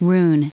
Prononciation du mot rune en anglais (fichier audio)
Prononciation du mot : rune